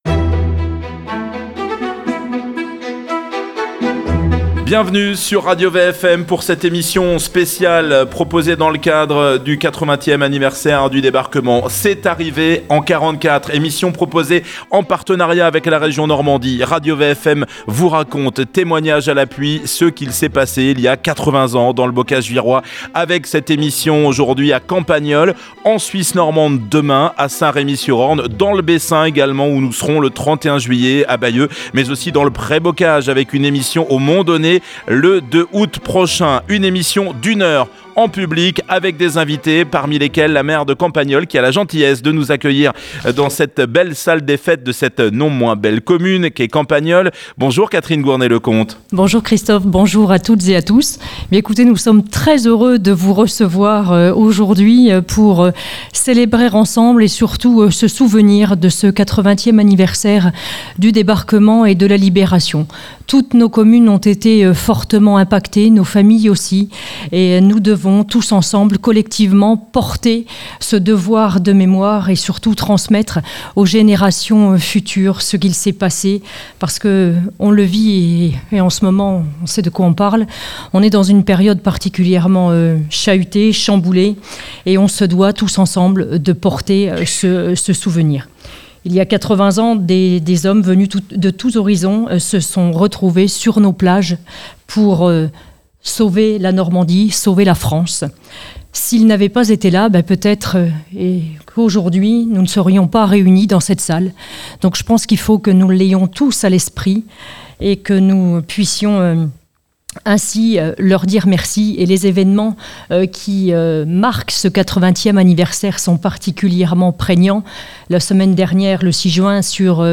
Émission exceptionnelle diffusée Samedi 15 Juin à Campagnolles . Témoignages, récits de la libération du Bocage.